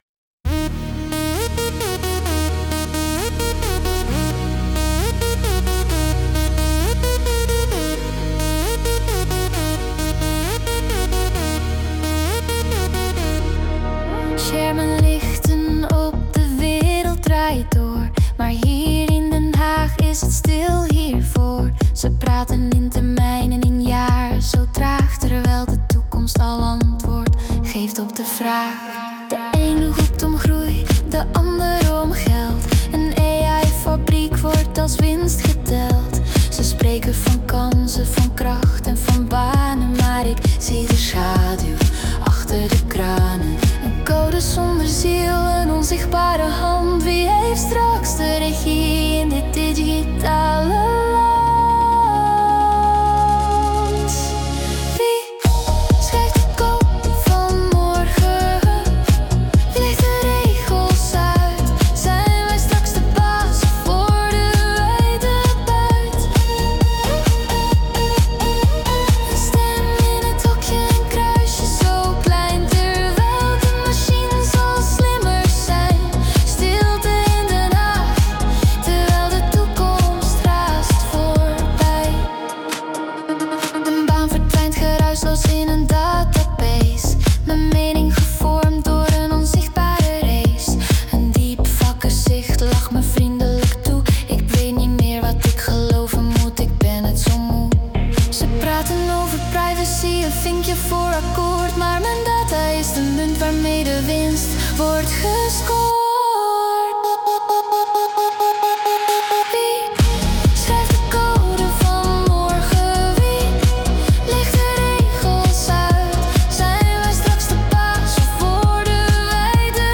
Om dat te laten horen heb ik de kern van dit blog vertaald naar muziek. Samen met Google Gemini 2.5 Pro en AI-componist SUNO creëerde ik ‘De Code van Morgen’: een nummer vanuit het perspectief van de burger die zich afvraagt wie er eigenlijk aan de knoppen draait.